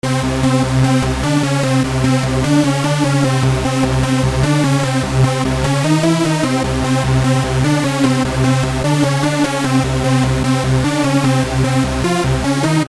Tag: 150 bpm Hardstyle Loops Synth Loops 2.16 MB wav Key : Unknown